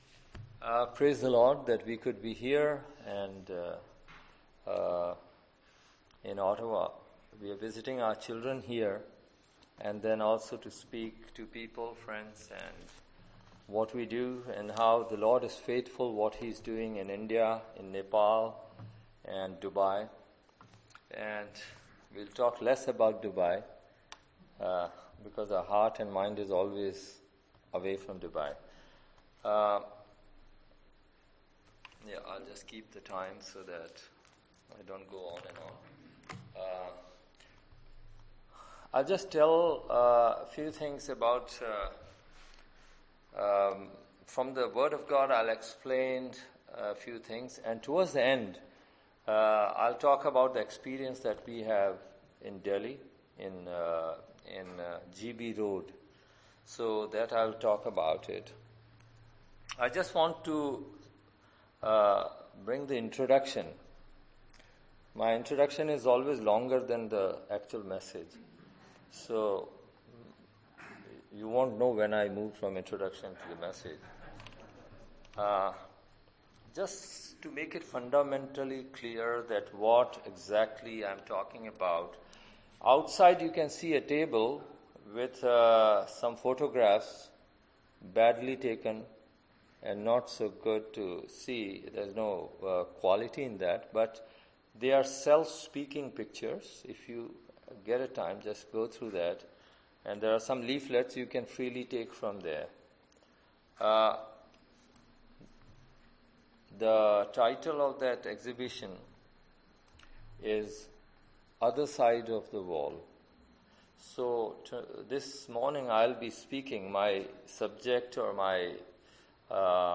Matthew 16:24 Service Type: Sunday AM Topics: Discipleship